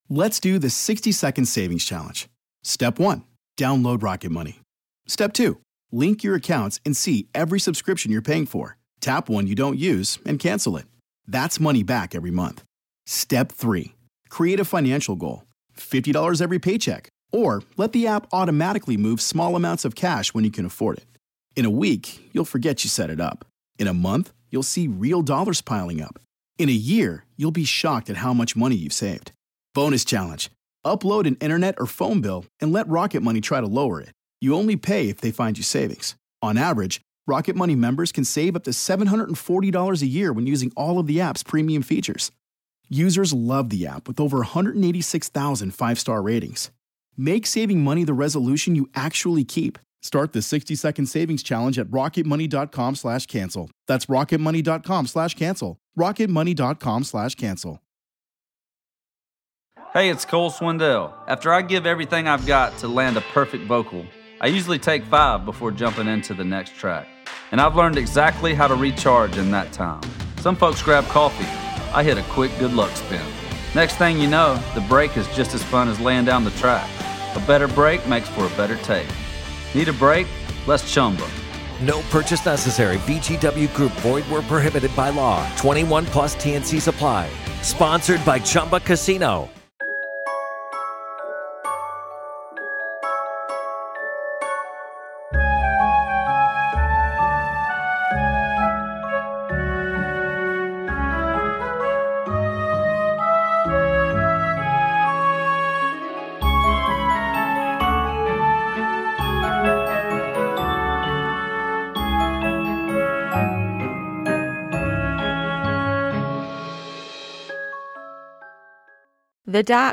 Disclaimer- slight swearing, it gets gross so avoid eating whiles you listen!